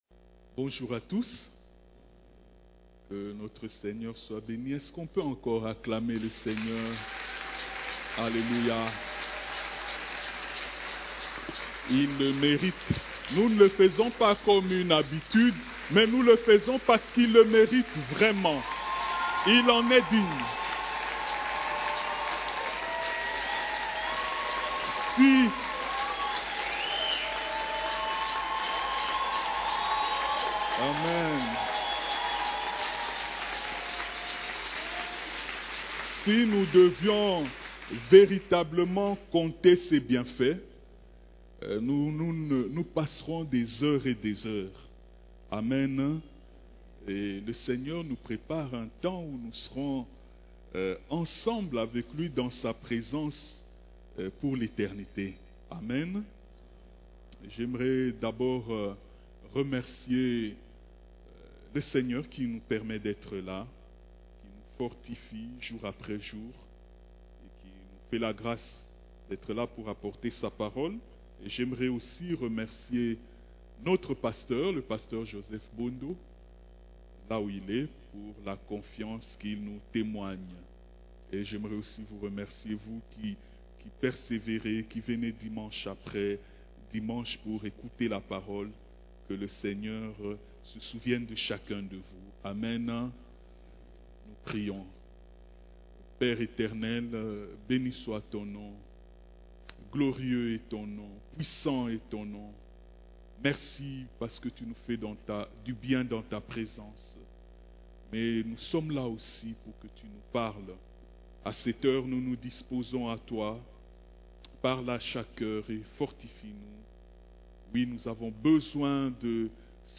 CEF la Borne, Culte du Dimanche, Christ en nous l'espérance de la gloire